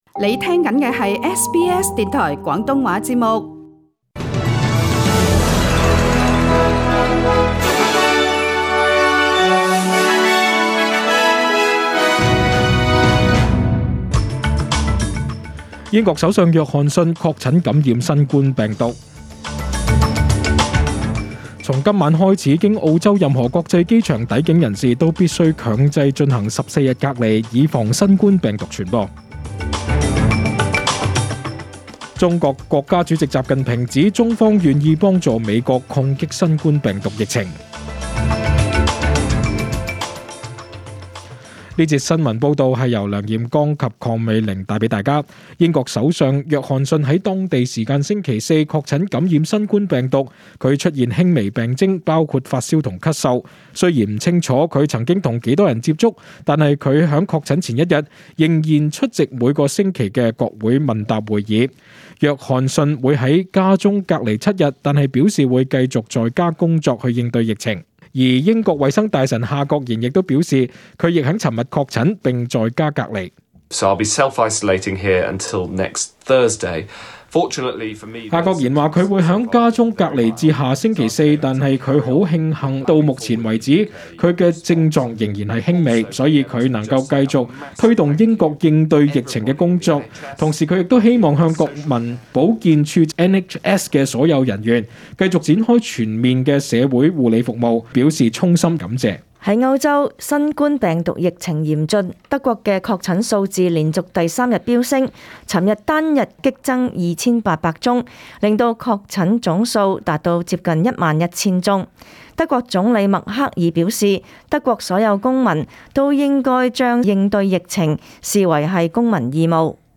SBS中文新闻（三月二十八日）
请收听本台为大家准备的详尽早晨新闻。